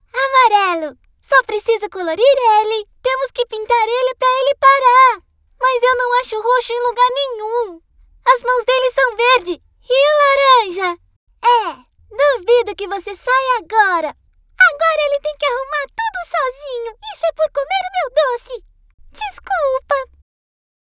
Voces infantiles de Brasil